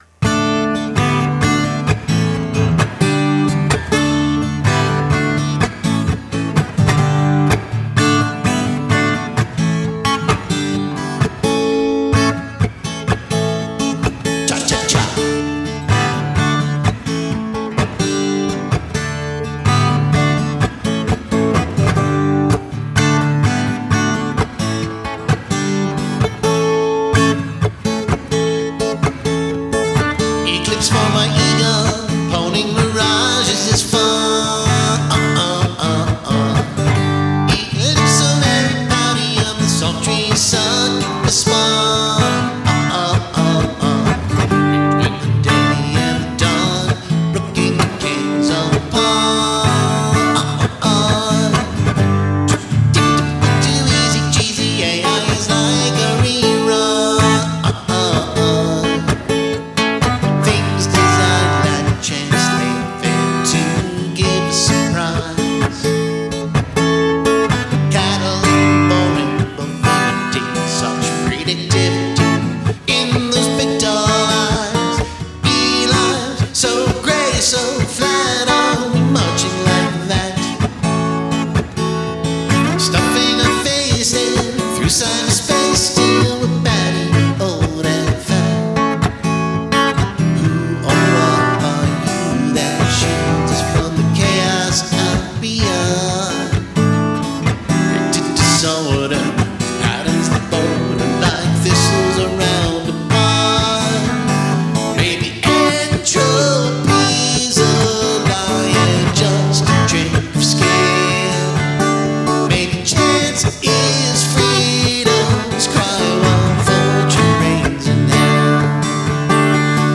Tempo: 128